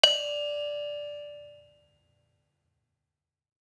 HSS-Gamelan-1
Saron-2-D5-f.wav